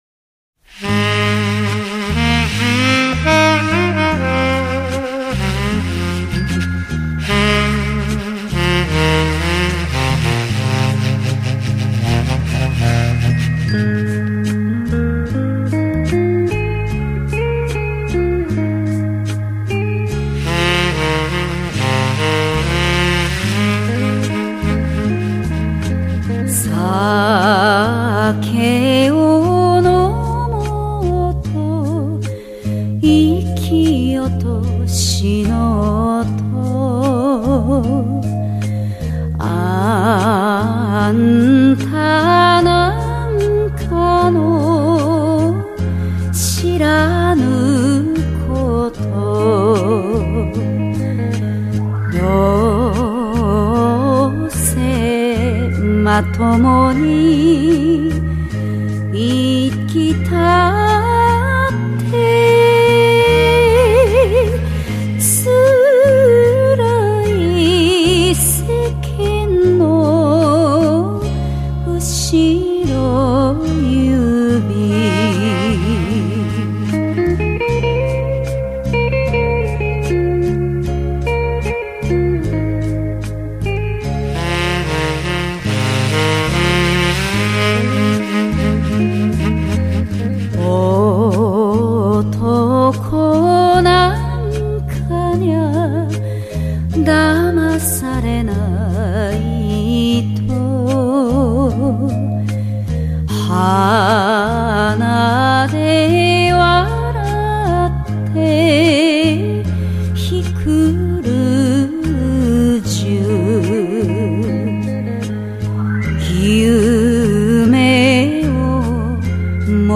[1/9/2009]来一首：慢悠悠的，萨克斯伴奏的，日本歌曲（也挺不错！）